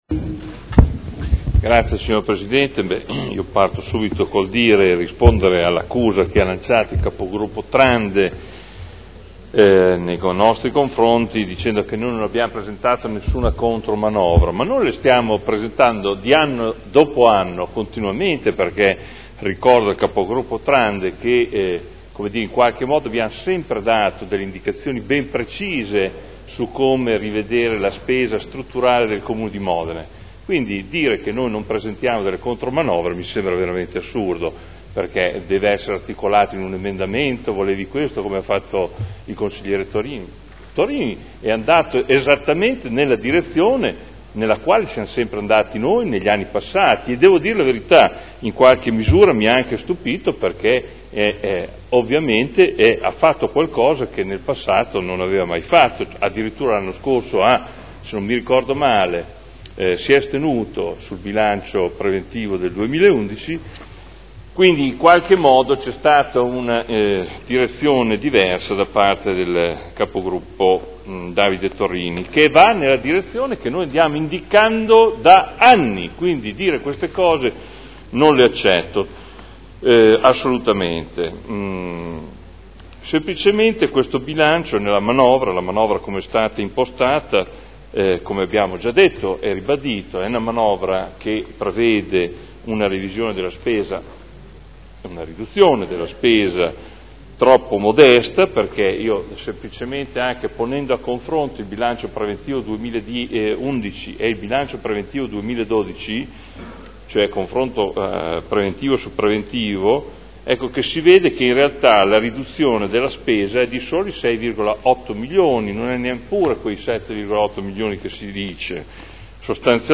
Adolfo Morandi — Sito Audio Consiglio Comunale